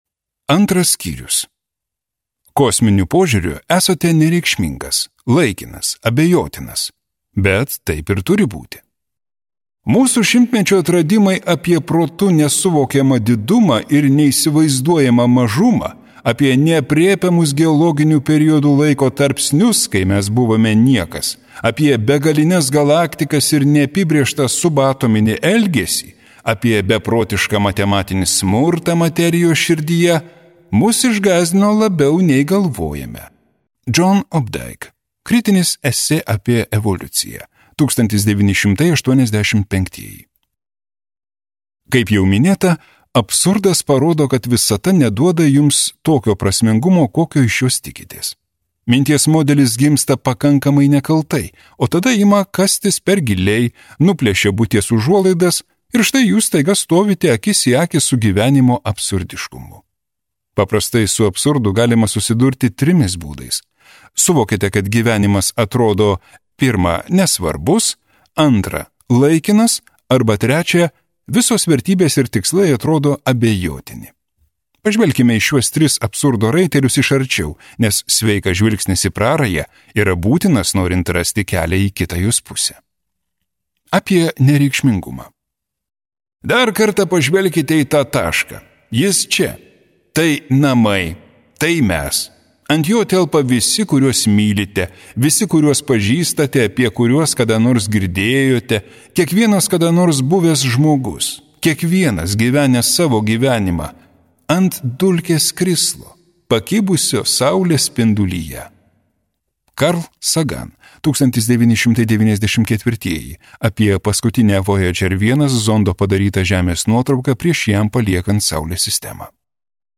Audio knyga